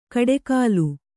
♪ kaḍekālu